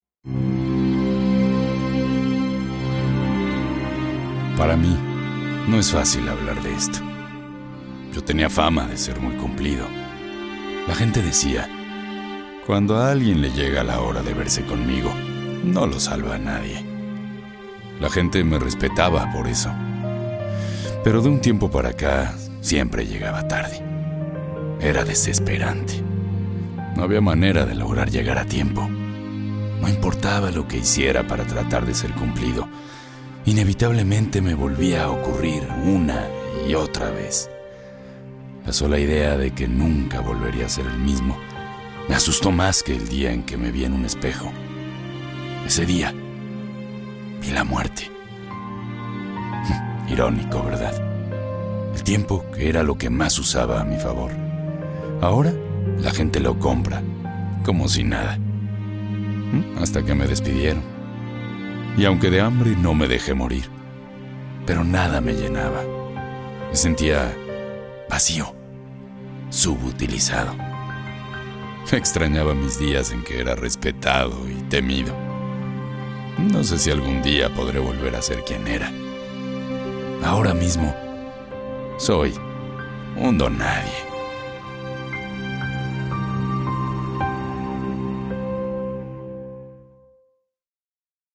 Narration character
Spanish - Neutral